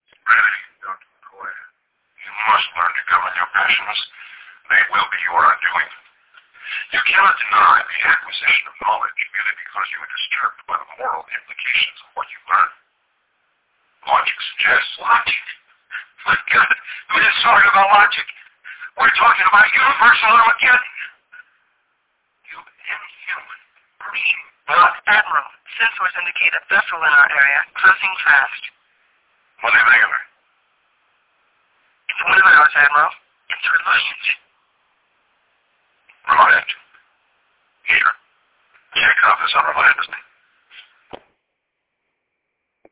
Quality is low, but the rarity of these materials makes them an absolute treasure.